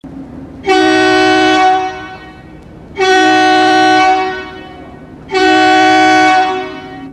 Другие рингтоны по запросу: | Теги: гудок, поезд, Train
Категория: Различные звуковые реалтоны